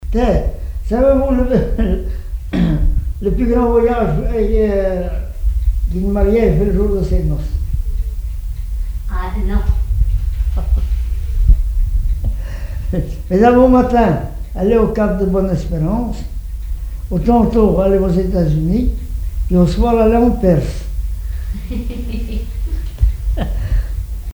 Genre formulette
Catégorie Récit